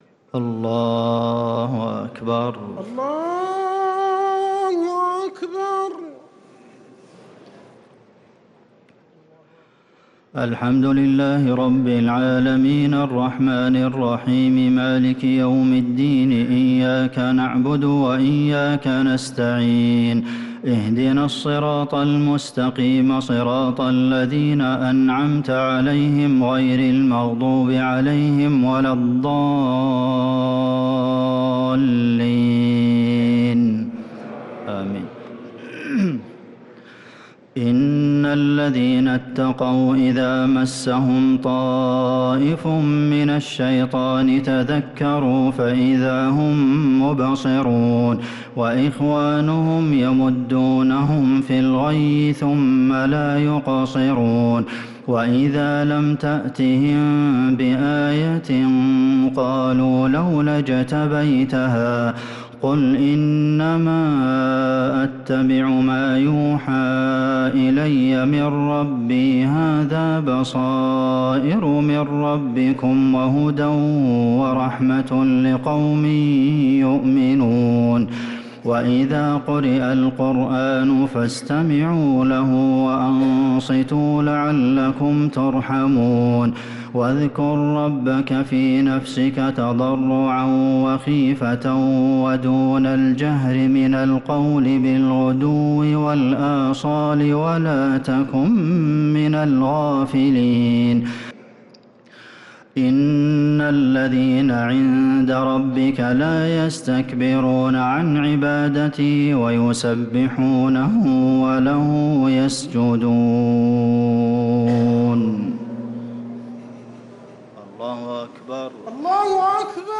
تراويح ليلة 12 رمضان 1446هـ من سورتي الأعراف (201-206) و الأنفال (1-40) | taraweeh 12th niqht Surat Al-Araf and Al-Anfal 1446H > تراويح الحرم النبوي عام 1446 🕌 > التراويح - تلاوات الحرمين